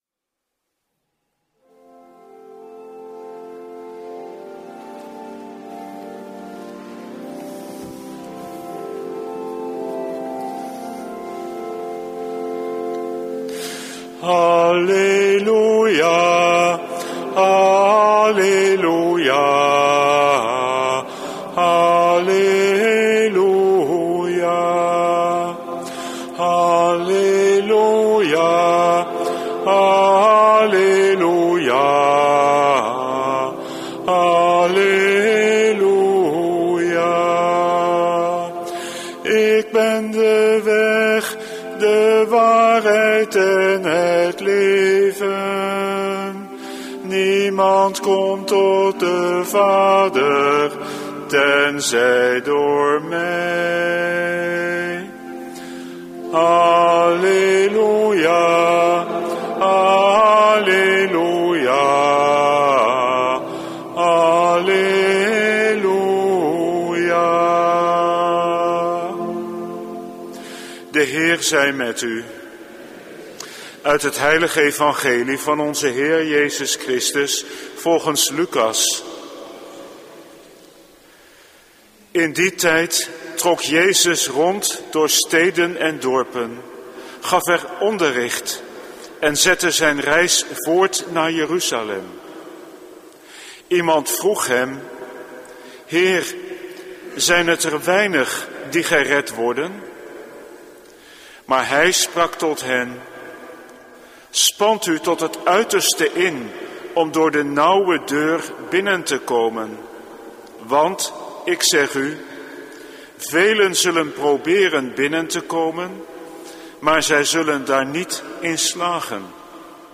Preek 21e zondag, door het jaar C, 20/21 augustus 2016 | Hagenpreken